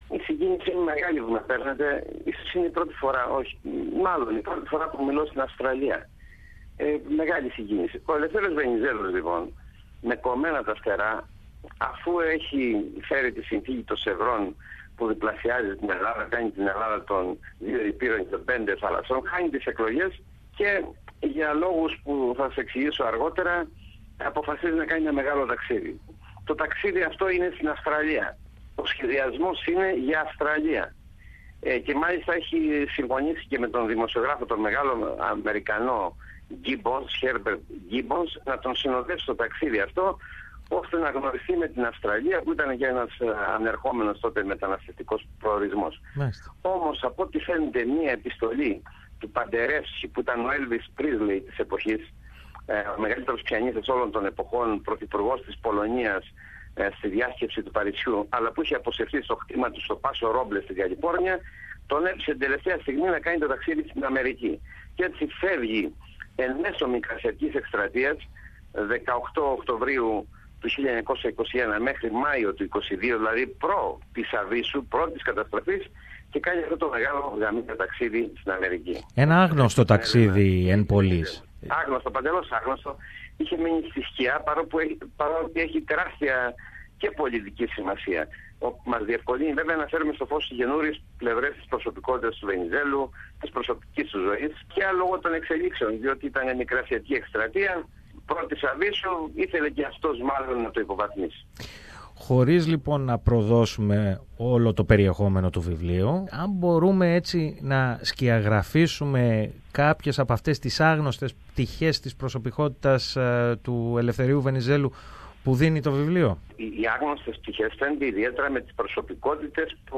This years marks the 80th anniversary of the death of the great Greek statesman, Eleftherios Venizelos. On this occasion, we spoke to writer and former Greek MP, Mimis Androulakis, whose latest book titled 'The Honeymoon' (Taksidi tou Melitos) reveals an unknown trip of Eleftherios Venizelos, with an Australian twist.